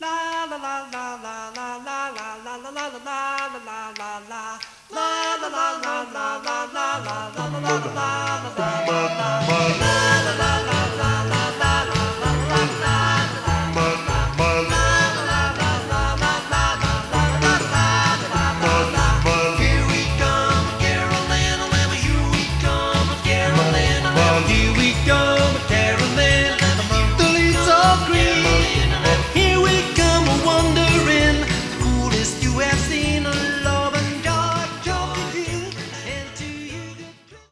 A Medley